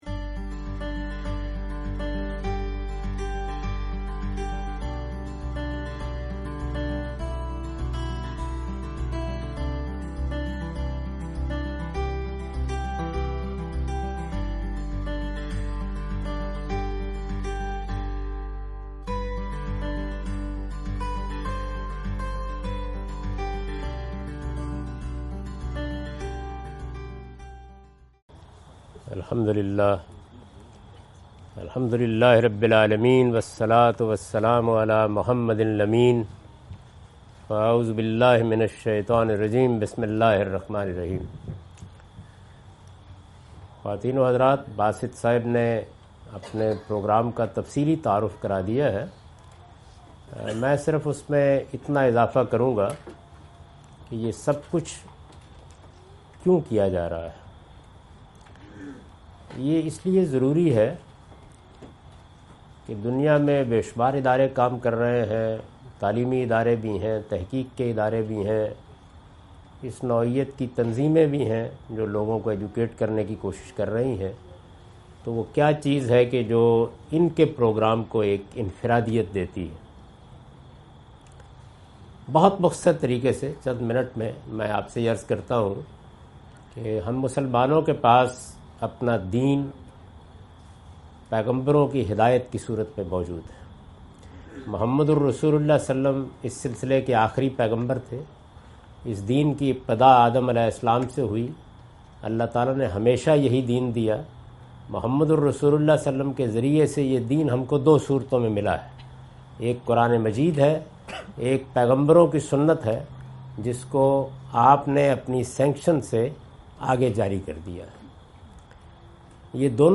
Introduction to Hadith Project of Al-Mawrid an Interactive Session
In this video Javed Ahmad Ghamidi answers the questions during his US visit in New York, 01 Oct 2017.